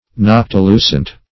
noctilucent - definition of noctilucent - synonyms, pronunciation, spelling from Free Dictionary